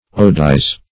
Search Result for " odize" : The Collaborative International Dictionary of English v.0.48: Odize \Od"ize\ ([=o]d"[imac]z or [o^]d"[imac]z), v. t. [imp.